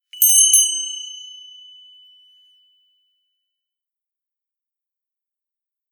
熊よけ鈴の効果音
チリリーンと綺麗な音が鳴ります。
和風効果音83.『熊よけ鈴③』